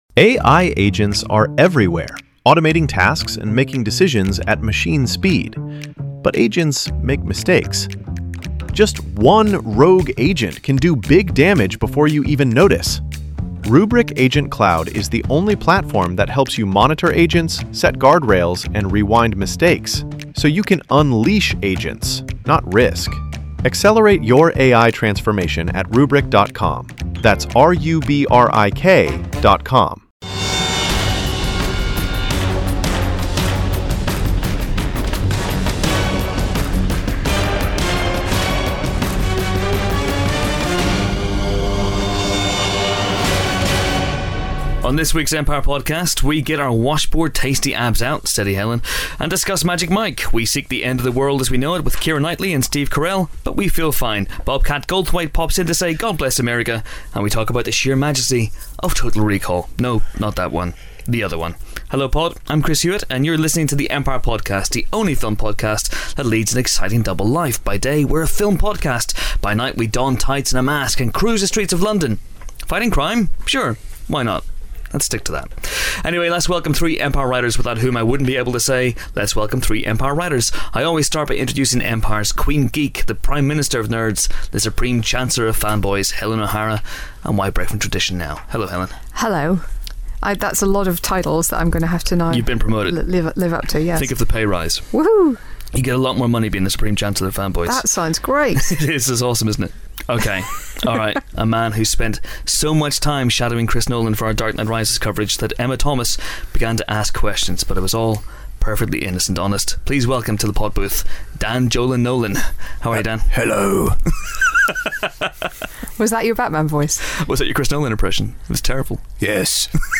This week's episode sees the Empire Podcast team discussing such important things as movie collectibles, stripping on film and how exactly continental drift works. As well as all that, there are reviews of Ice Age 4, Magic Mike, God Bless America and a hilarious interview with the one and only Bobcat Goldthwait.